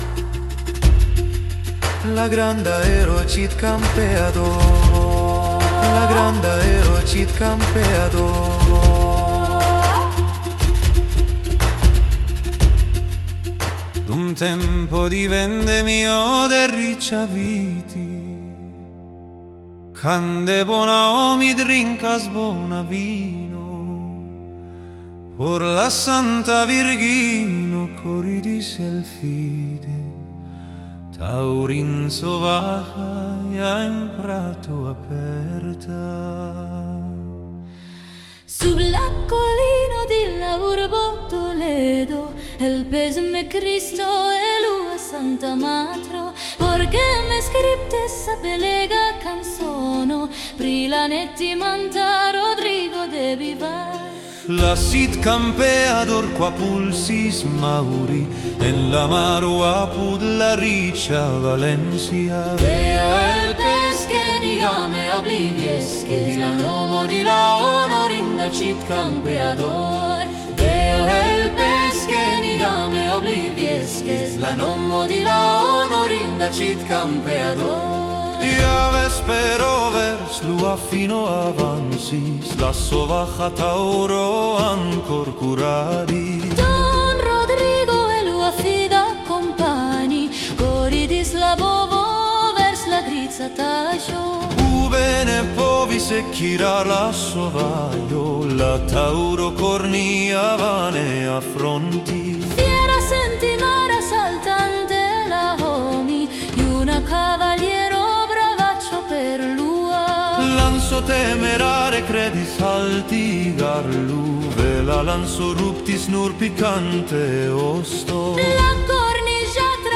rakonto epiko